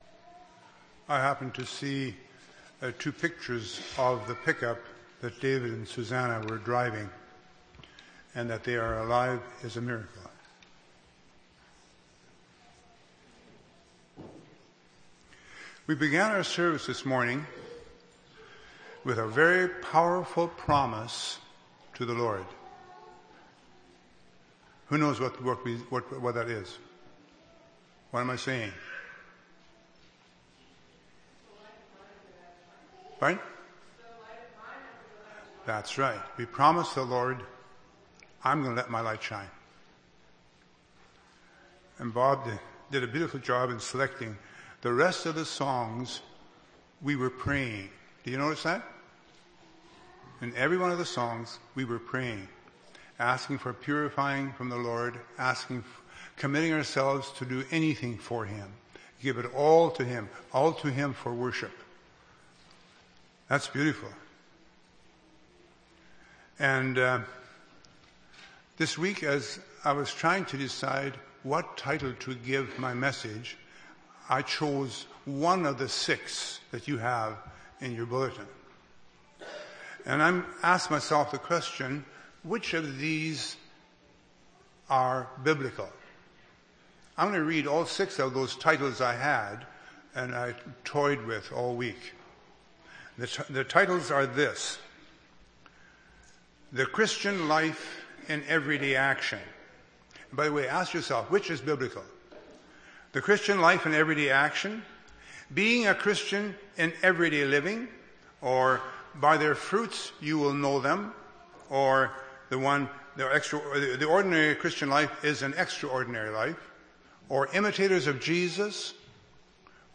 March 27, 2011 – Sermon